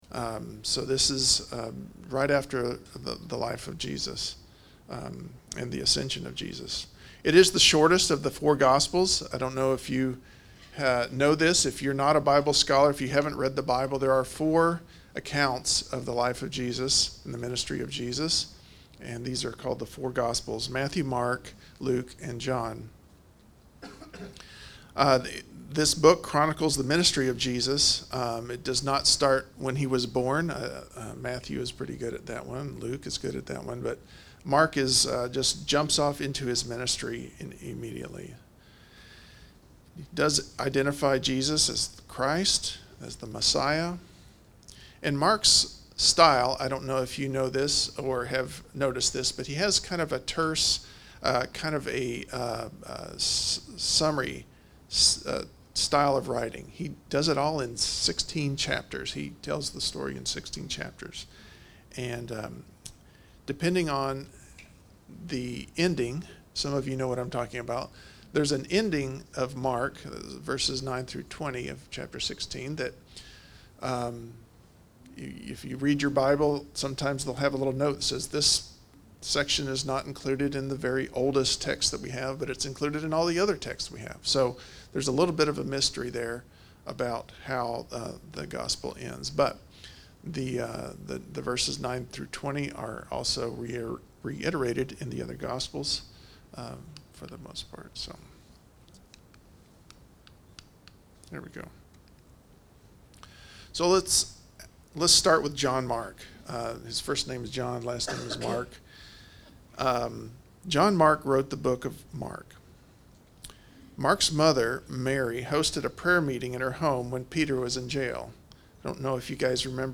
The Kingdom of God in Mark Service Type: Sunday Morning « Genesis